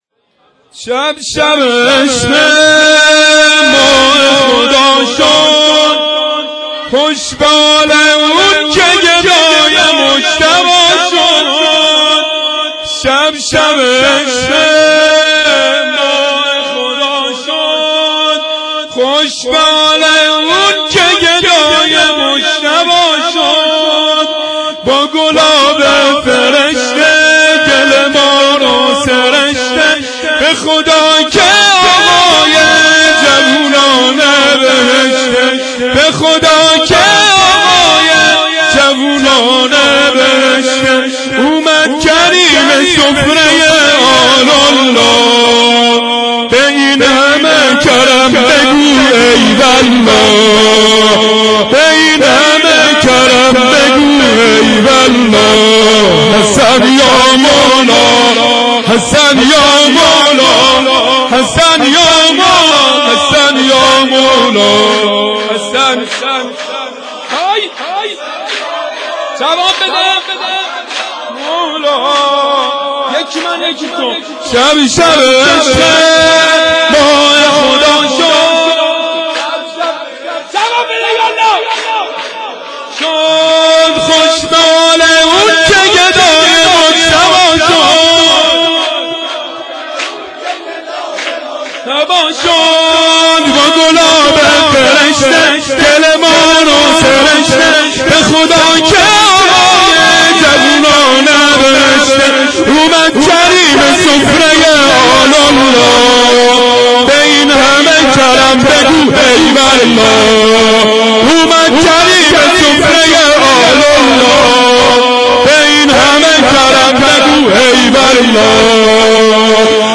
شام میلاد امام حسن - رمضان 1392
sham-e-milad-e-emam-hasan-1392-d.wma